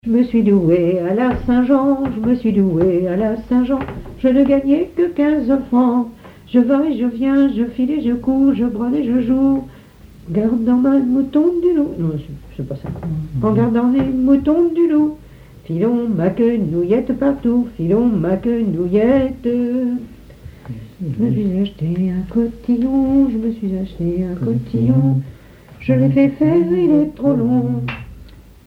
Pièce musicale inédite